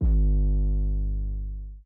SU_808 (Rich Homie).wav